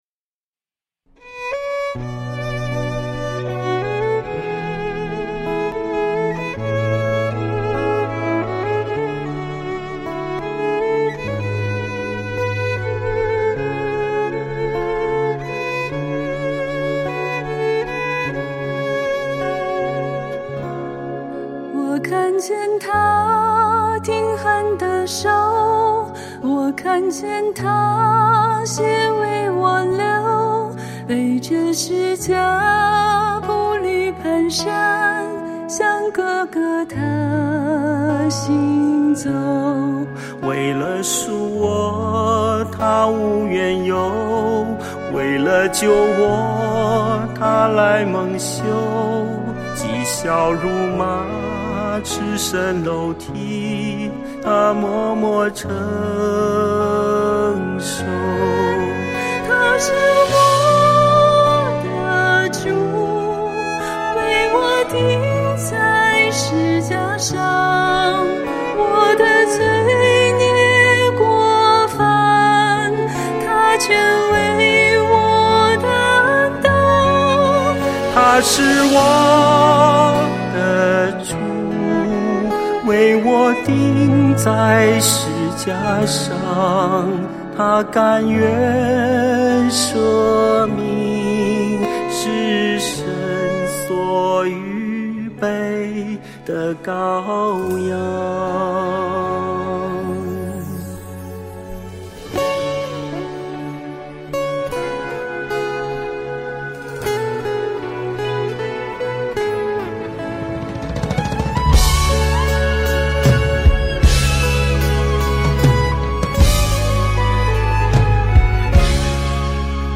不知道两位弟兄姐妹深情地诠释有没有感动到你？